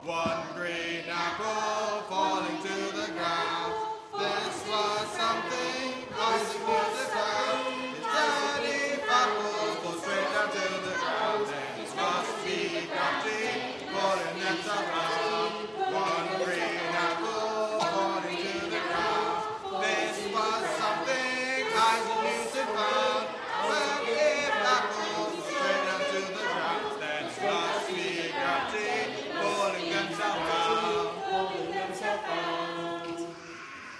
Singing History Concert 2016: One Green Apple 3
Lyrics composed by the CBBC's Horrible Histories song writer called Dave Cohen To the tune of children's nursery rhyme 'One Green Bottle'